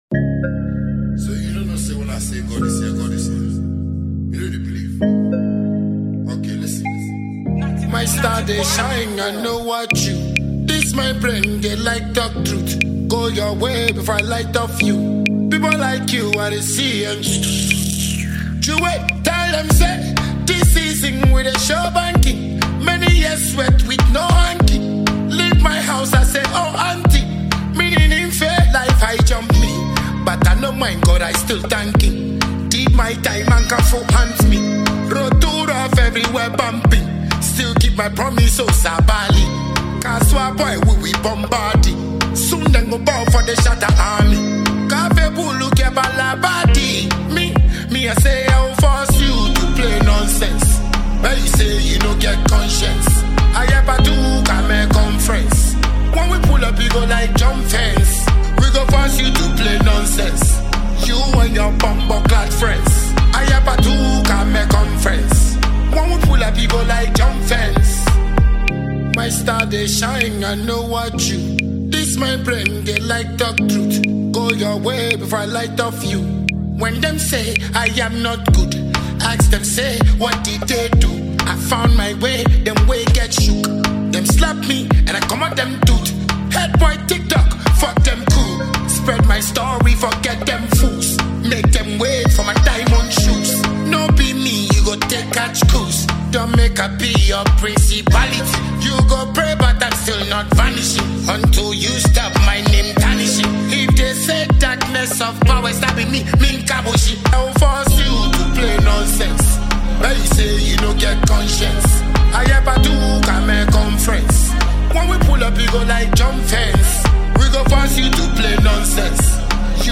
Ghanaian dancehall songwriter and musician